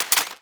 GUNMech_Reload_06_SFRMS_SCIWPNS.wav